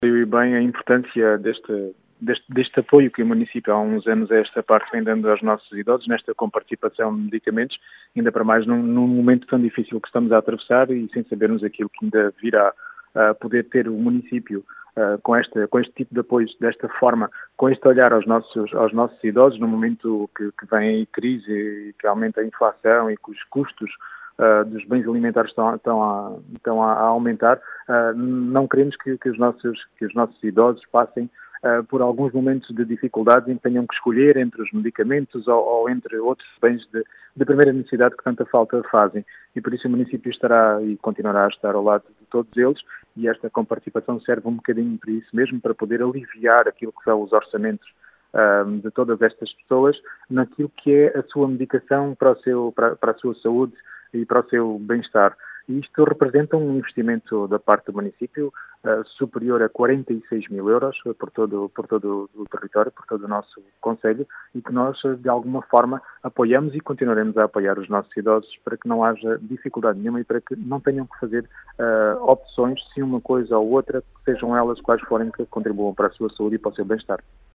Em declarações à Rádio Vidigueira, o presidente da Câmara Municipal de Vidigueira destacou a importância deste apoio, orçado em 46 mil euros, com o objectivo de “aliviar” o orçamento destas pessoas.